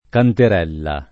canterella
canterella [ kanter $ lla ] o cantarella [ kantar $ lla ]